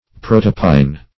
Search Result for " protopine" : The Collaborative International Dictionary of English v.0.48: Protopine \Pro"to*pine\, n. [Proto- + opium.]